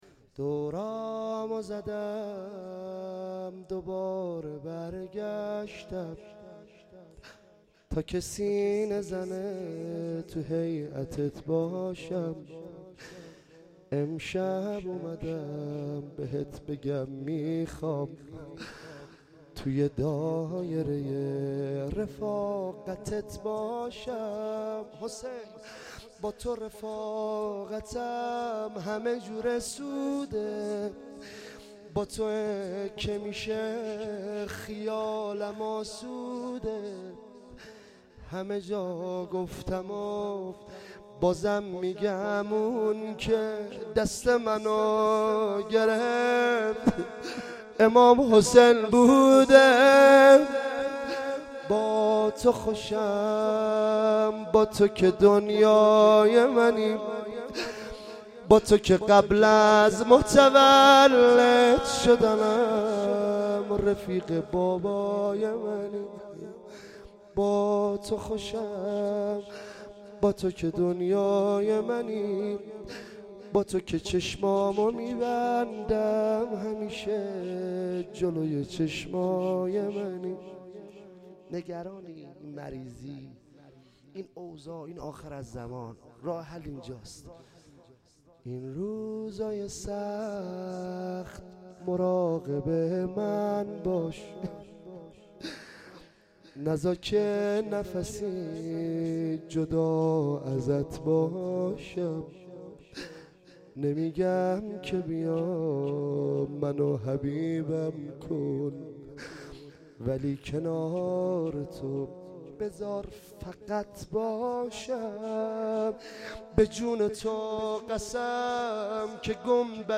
عنوان ولادت حضرت زهرا ۱۳۹۹ – شاندیز مشهد
زمزمه